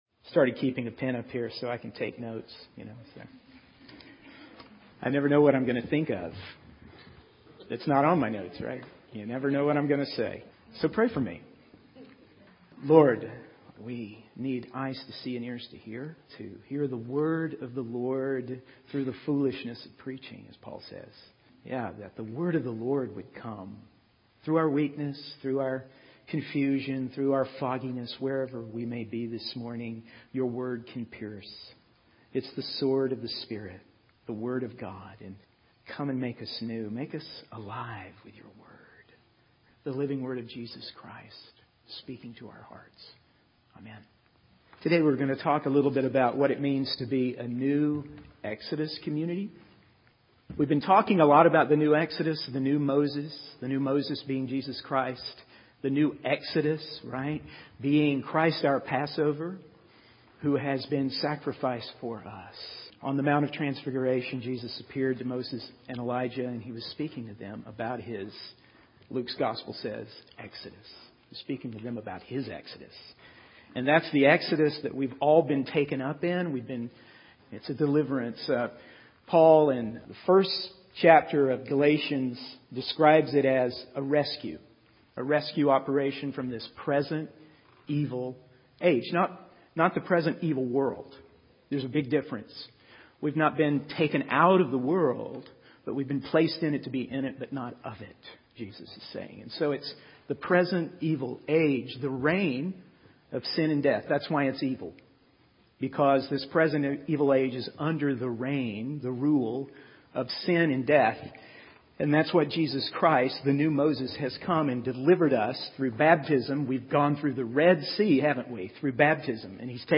In this sermon, the speaker shares a personal anecdote about his dog and a bone to illustrate the destructive nature of biting and devouring one another. He connects this behavior to the false teachings that were being spread in the Galatian churches, suggesting that they were causing division and harm within the community. The speaker emphasizes the importance of loving one's neighbor as oneself, quoting from the law to support this idea.